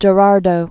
(jə-rärdō, -rä-)